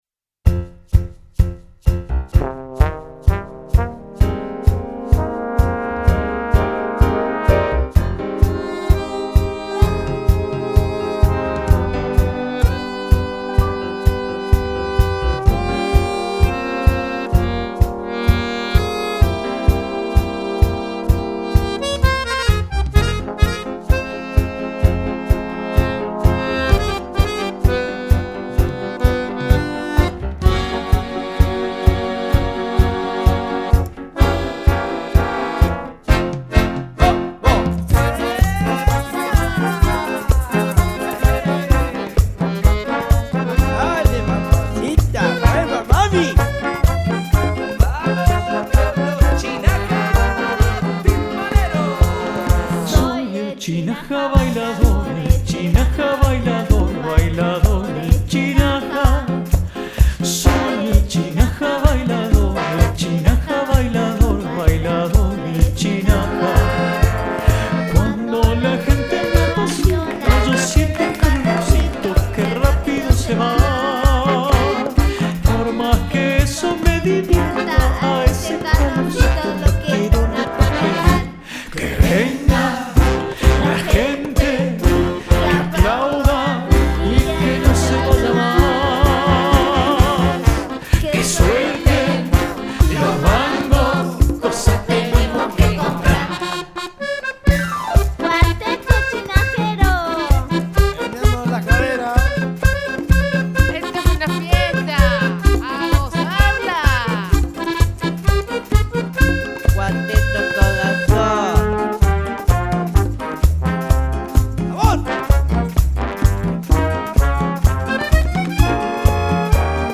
La banda de cumbia inclusiva largó este nuevo hit
cumbia retro
voz y conga